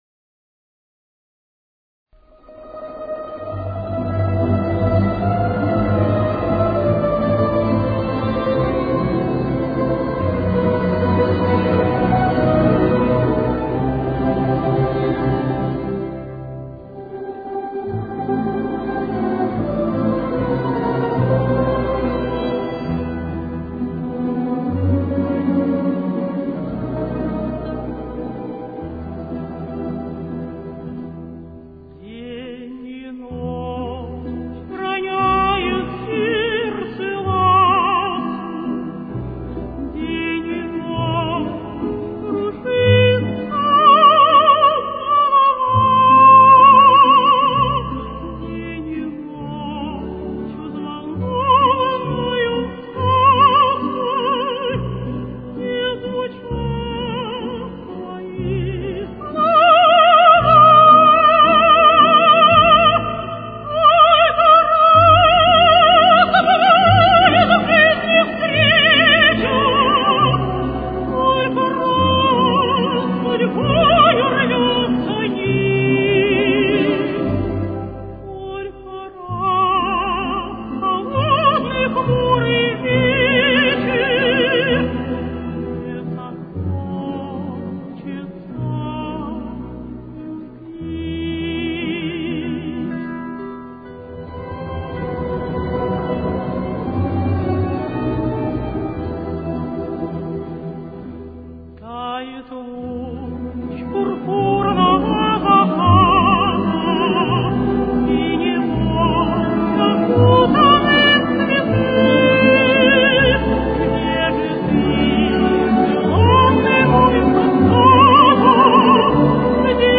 с очень низким качеством (16 – 32 кБит/с)
Темп: 69.